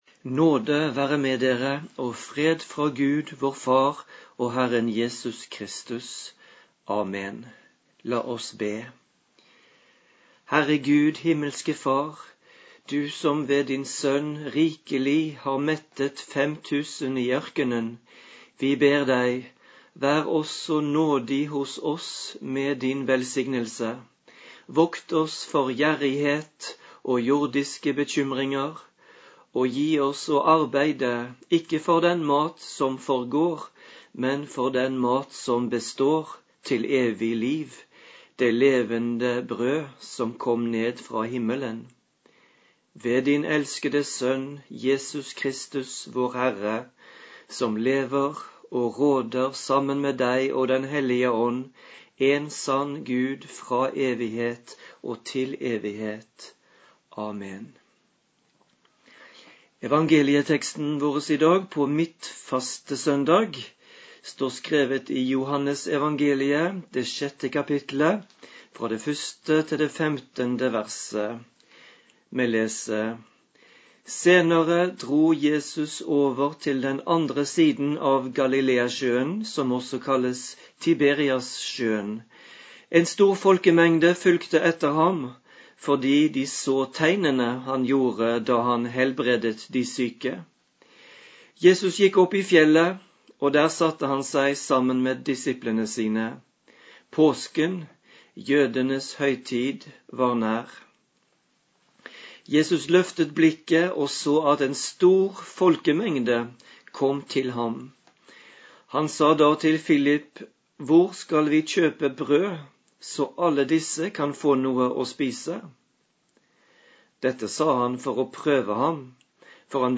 Preken på midtfastesøndag